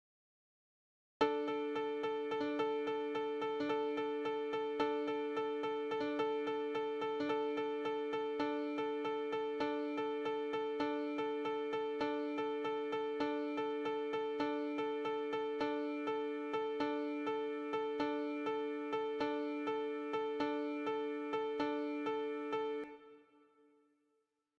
608. 39:24 = 3-13:8s